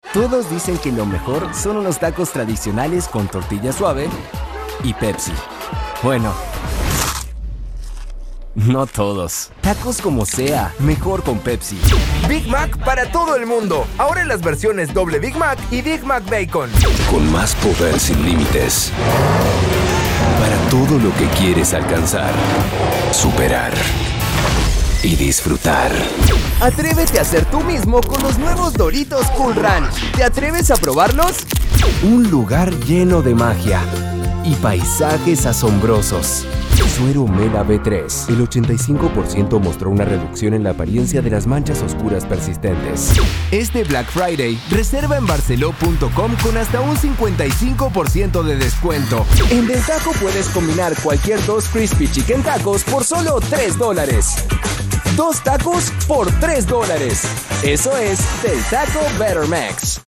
LATAM Spanish REEL 2025-2026
Young Adult
Commercial